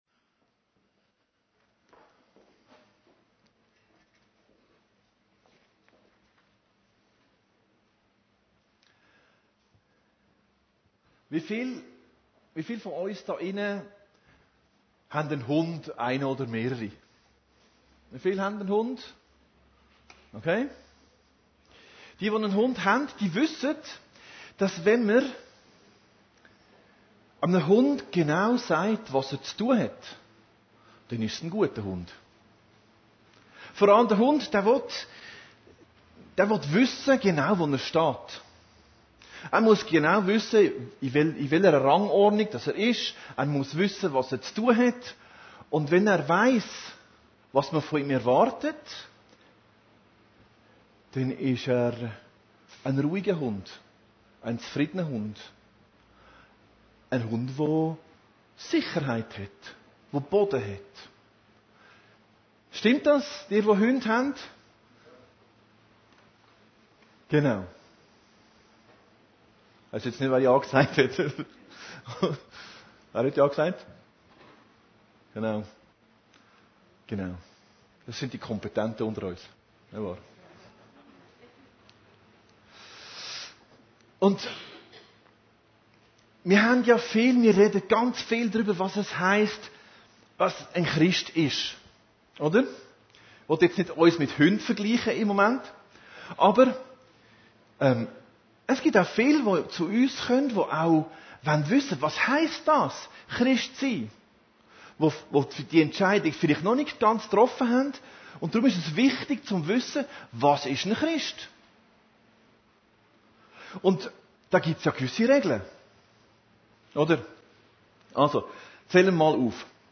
Predigten Heilsarmee Aargau Süd – Der freie Christ